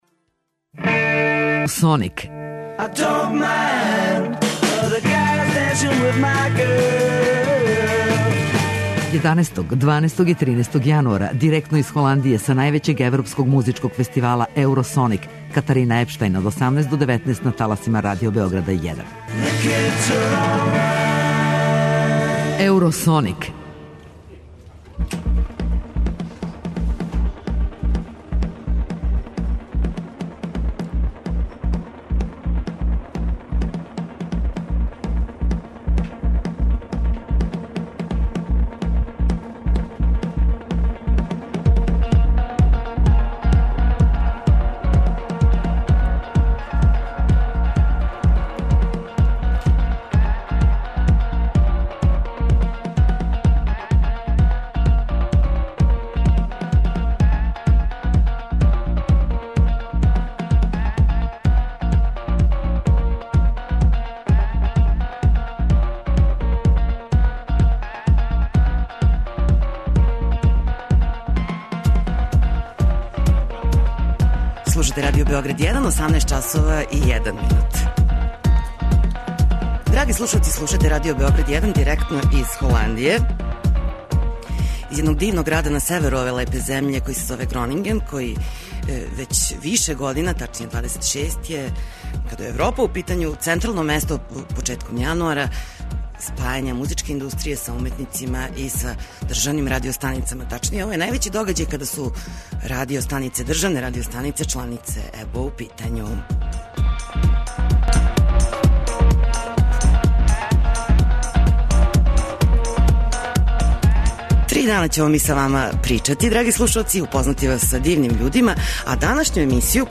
Радио Београд извештава уживо из Гронингена у Холандији, где се одржава један од најзначајнијих музичких фестивала у свету: Еуросоник. Овај јединствени фестивал не доводи глобалне комерцијалне звезде већ тражи нови квалитет у европској музици, а прати га и највеће окупљање стручњака из света музике.